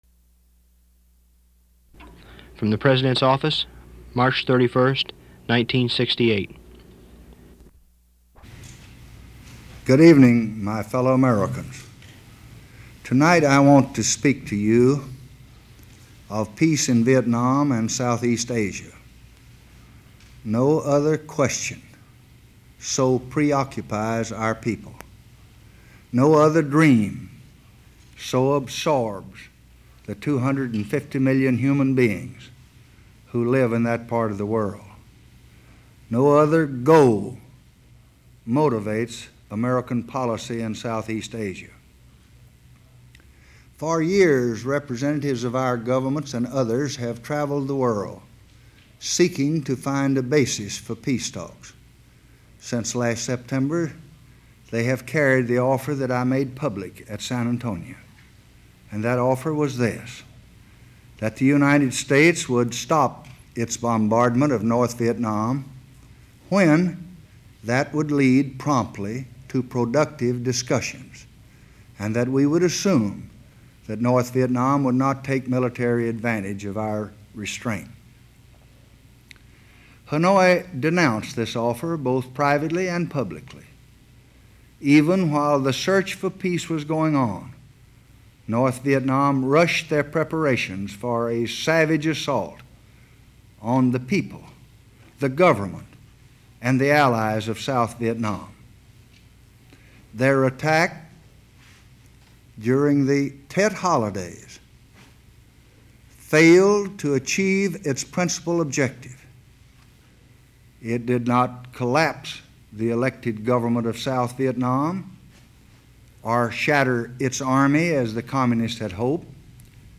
Lyndon Baines Johnson: Renunciation Speech
LyndonJohnsonRenunciationSpeech.mp3